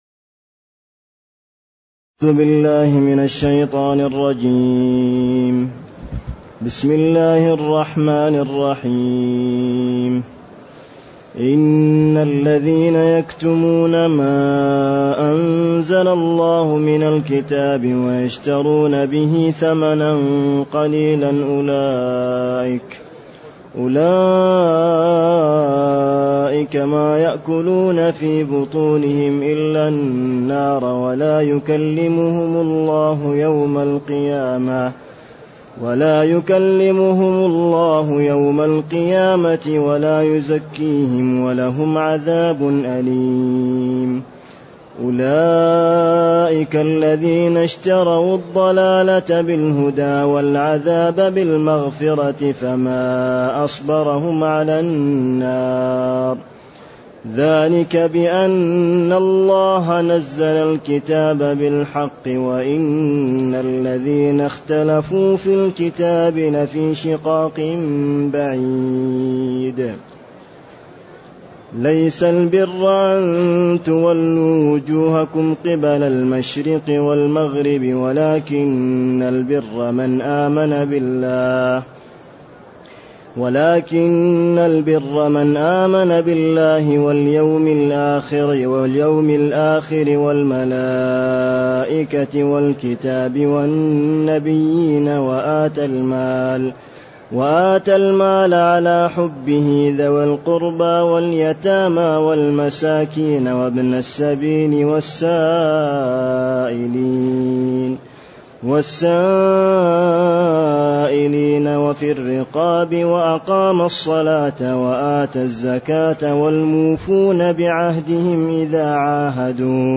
تاريخ النشر ١٨ رجب ١٤٢٨ هـ المكان: المسجد النبوي الشيخ